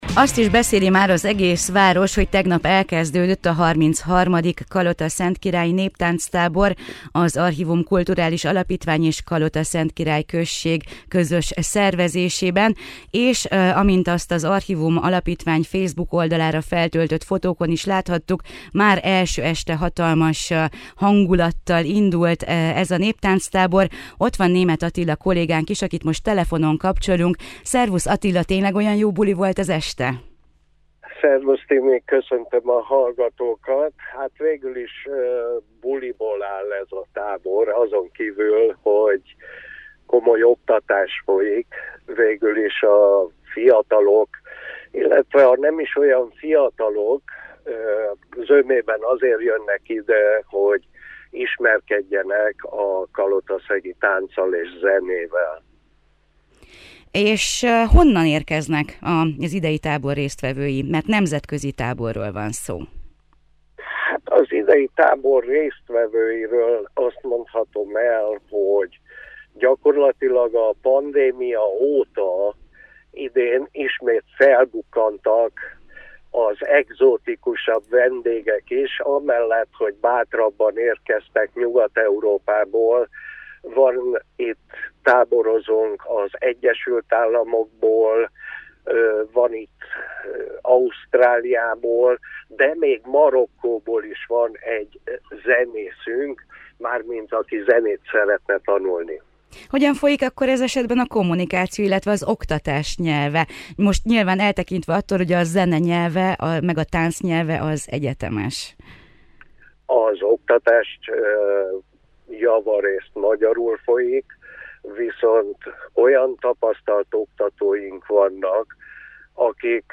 Beszélgetőtárs: